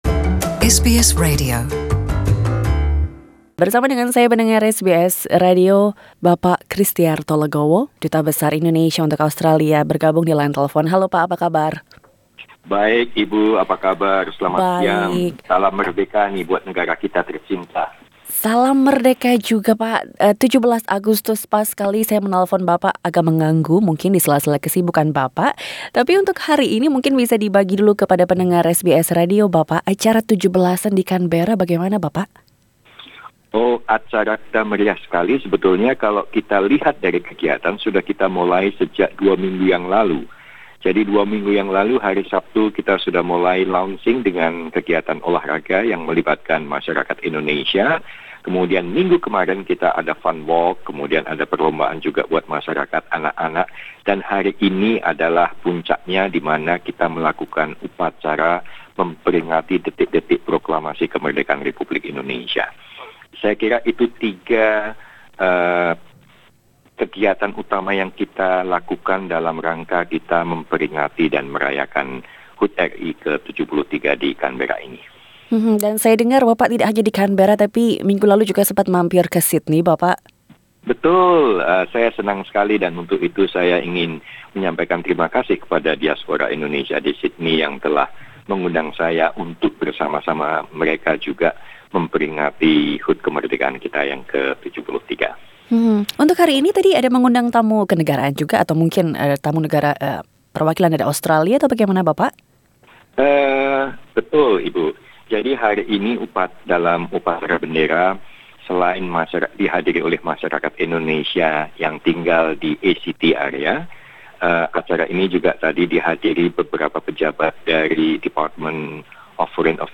Duta Besar Indonesia untuk Australia Bapak Yohanes Kristiarto Soeryo Legowo berbicara kepada SBS Indonesia tentang peringatan kemerdekaan Indonesia yang ke-73.